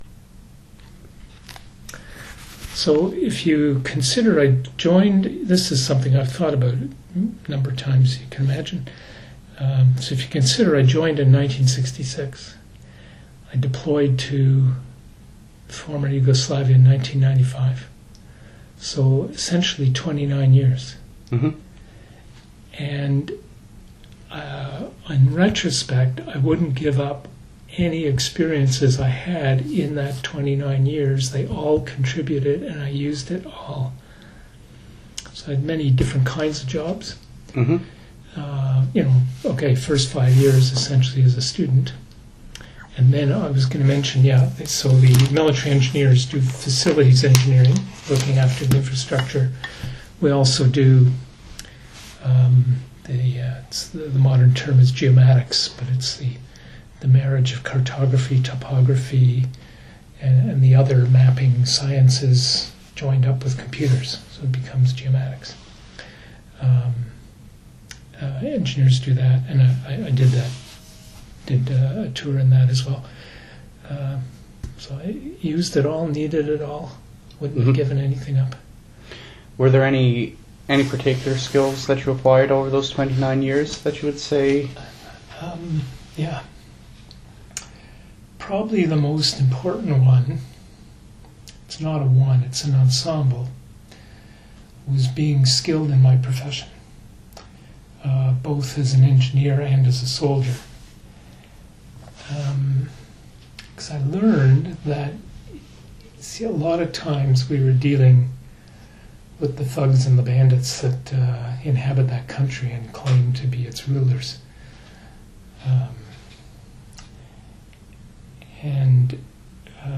Discusses RMC and training; begins discussing the duties of military engineers in the CF. End of first file due to telephone interruption.